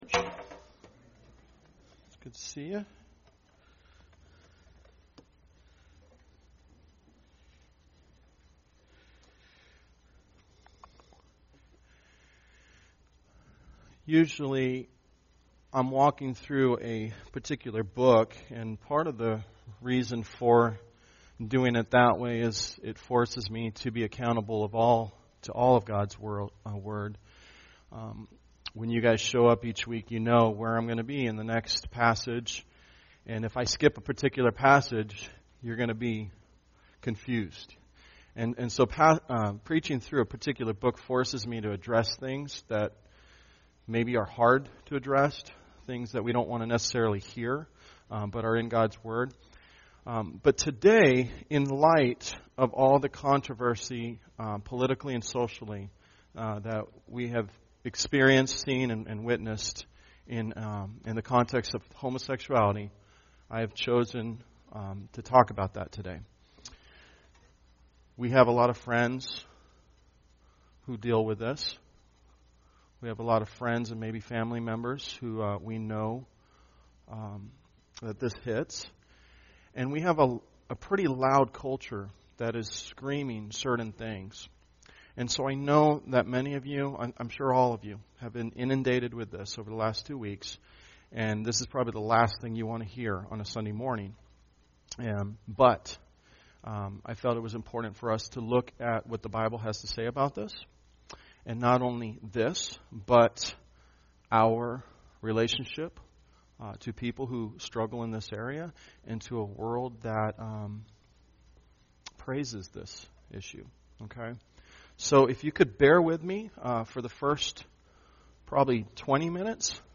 Tagged with Sunday Sermons